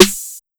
Go Head Snare.wav